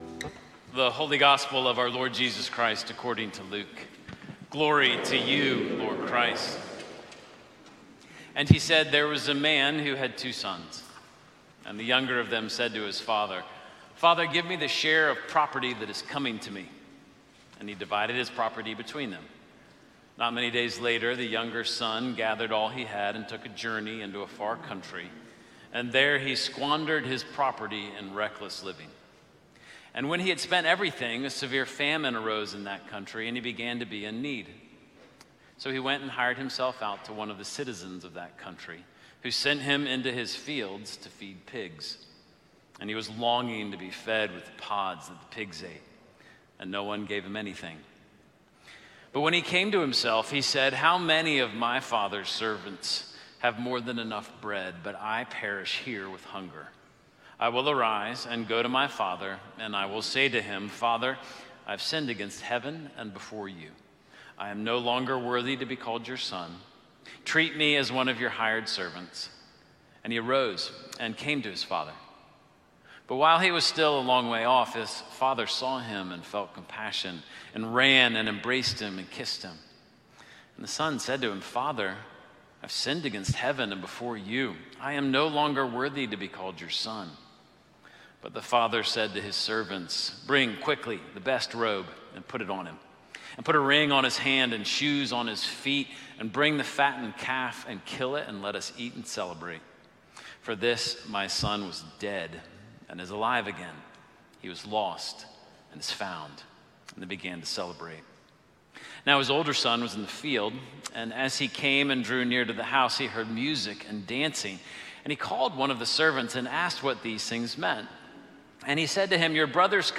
Sermons - Holy Trinity Anglican Church